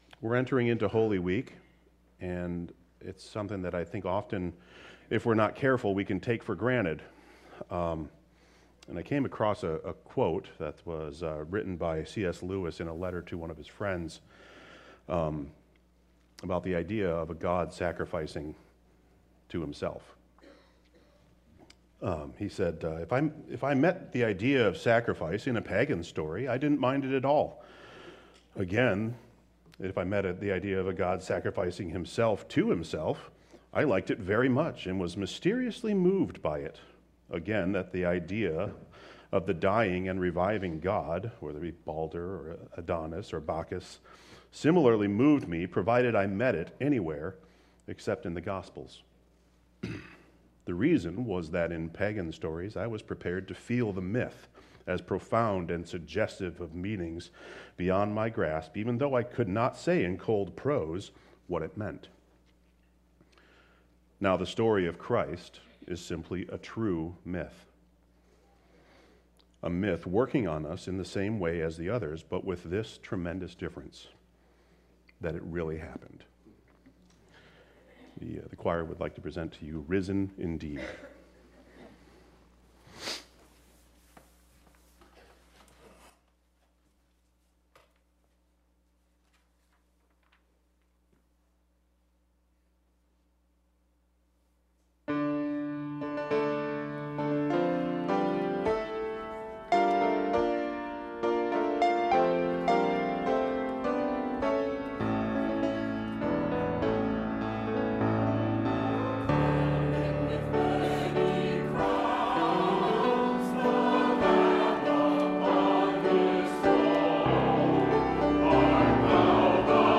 Easter Cantata "Risen Indeed" - Robinson Baptist
Cantata_RisenIndeed.mp3